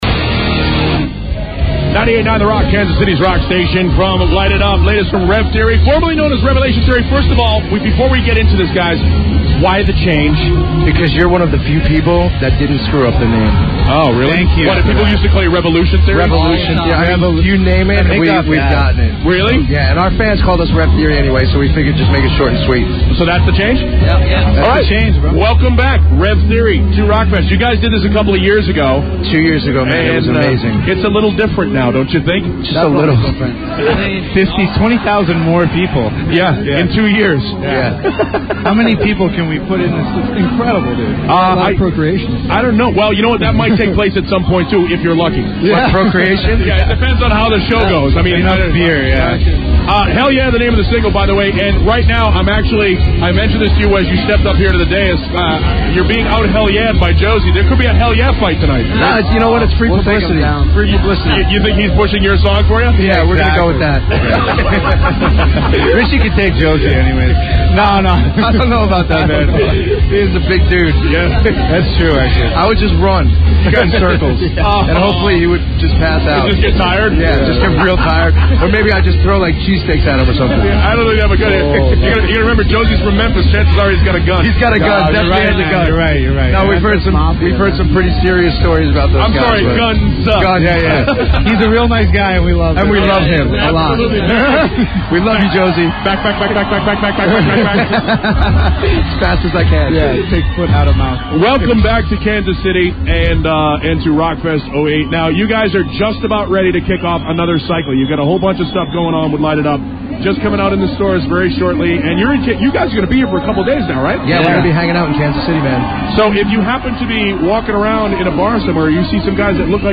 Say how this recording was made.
KQRC: Kansas City – Hanging @ Rockfest w/ Rev Theory